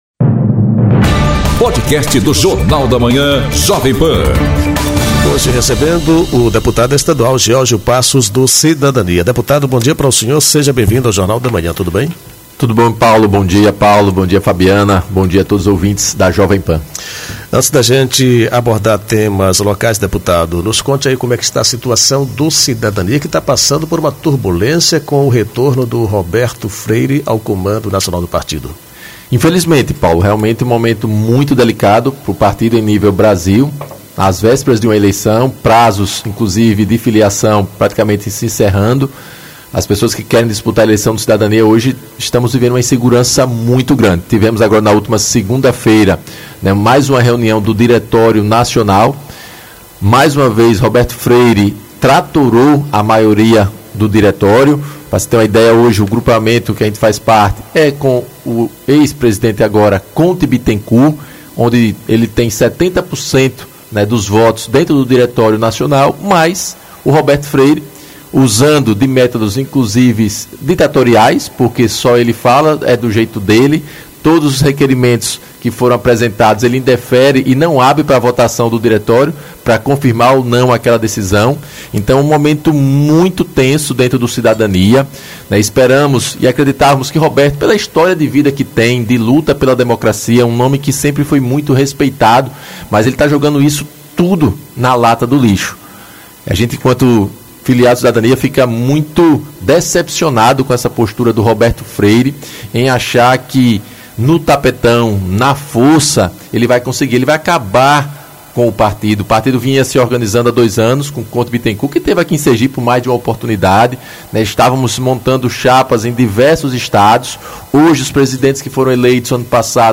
Entrevista com o deputado estadual Georgeo Passos. Ele fala sobre a crise no Cidadania, gestão Fábio Mitidieri e a possível candidatura de Valmir de Francisquinho ao Governo.